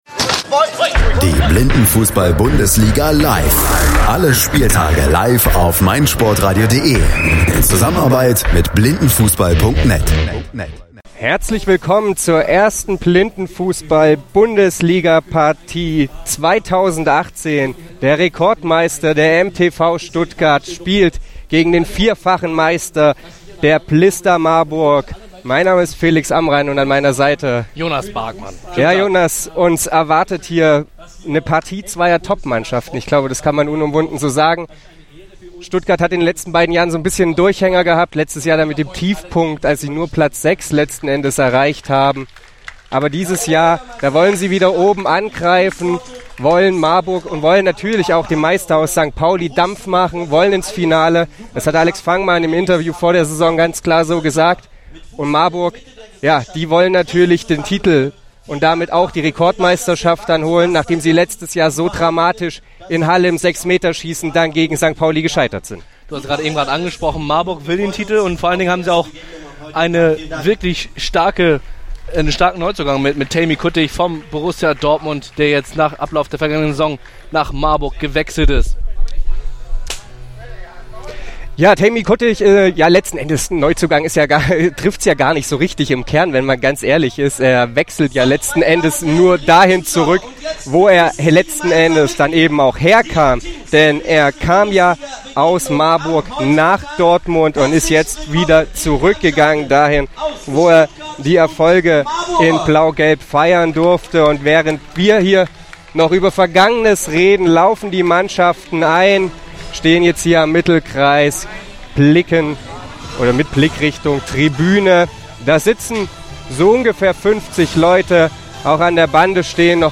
In der ersten Partie der Blindenfußball Bundesligasaison 2018 trafen mit dem MTV Stuttgart und blista Marburg 9 der 10 vergebenen Meisterschaften aufeinander.
blifu-relive-stuttgart-marburg.mp3